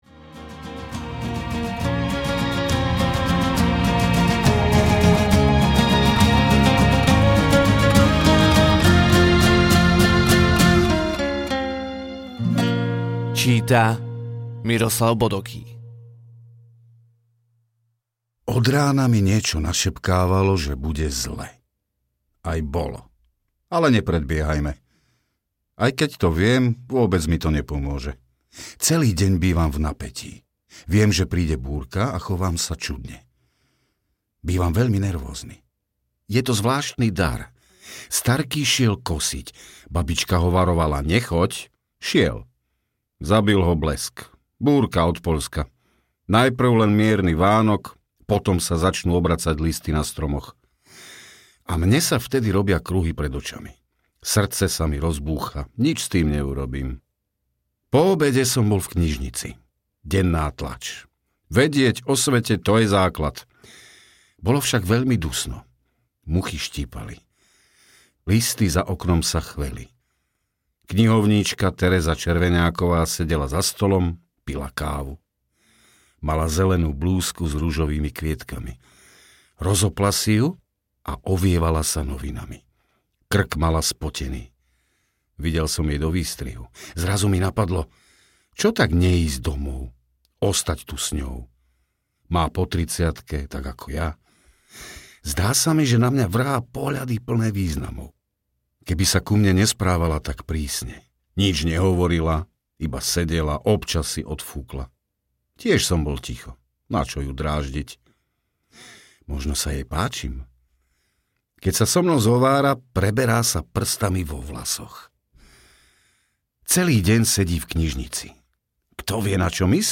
Motýľovci audiokniha
Ukázka z knihy